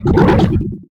CosmicRageSounds / ogg / general / combat / creatures / alien / he / attack1.ogg
attack1.ogg